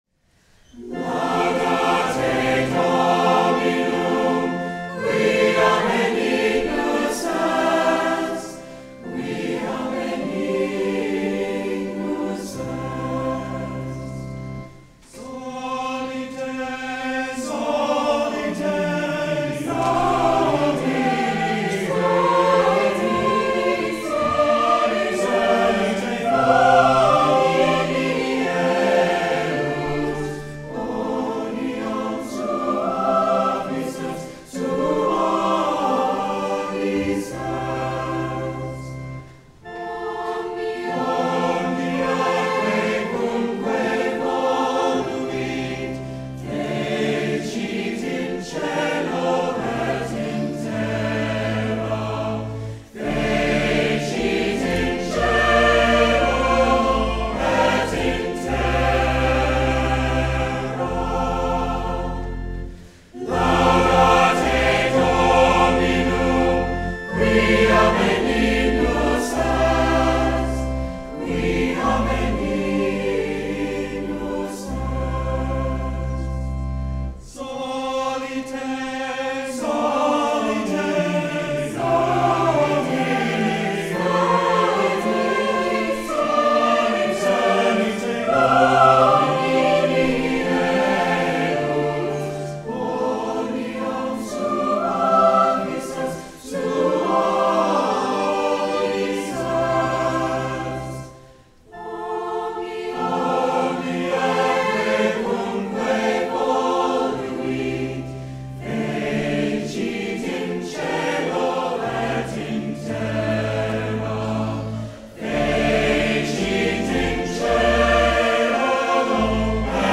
First Solemn High Mass
in a most magnificent and inspirational chorus